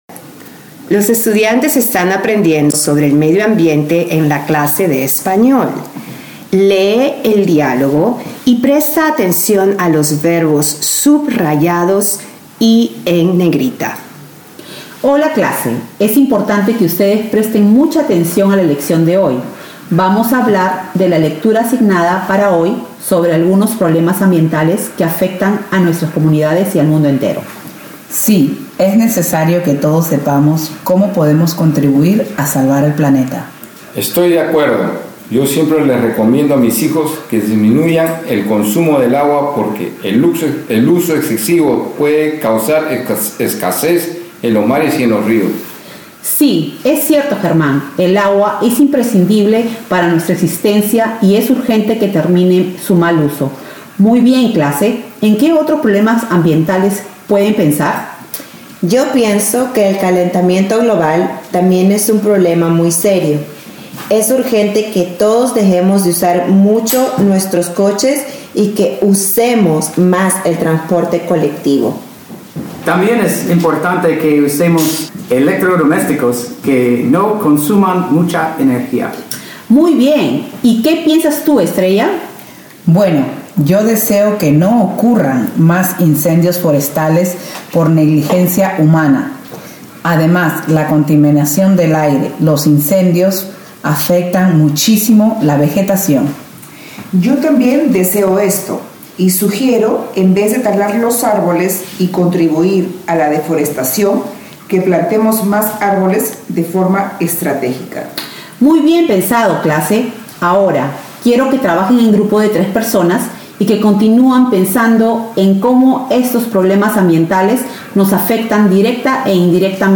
Contextos-Audio-for-Dialogue.mp3